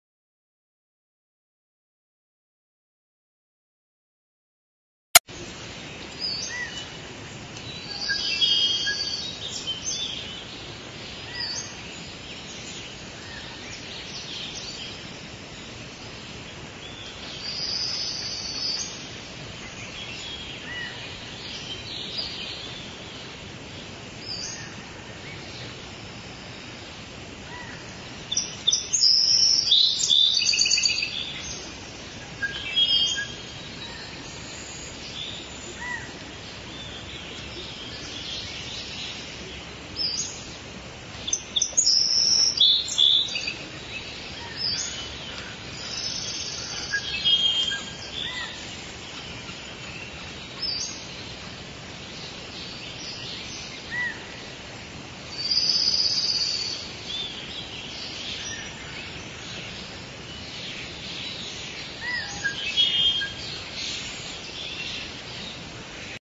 Rainforest Sound effect